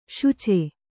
♪ śuce